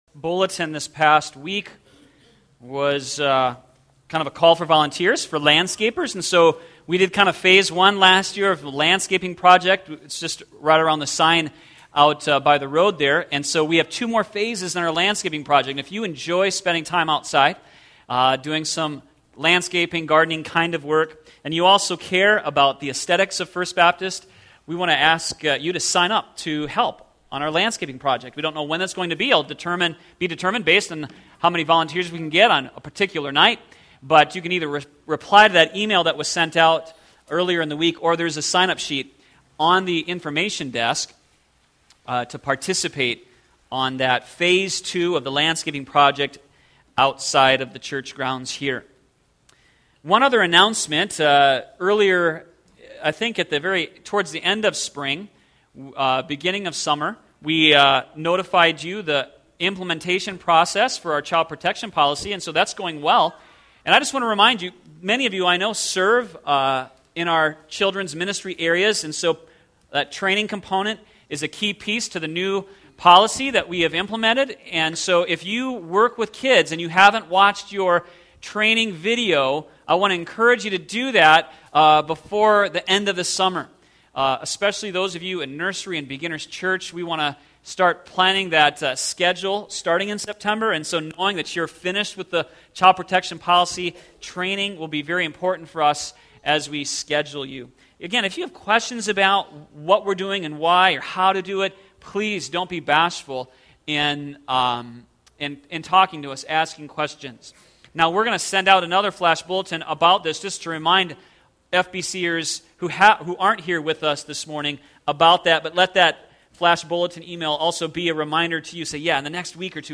sermon62914.mp3